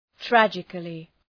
Προφορά
{‘trædʒıklı}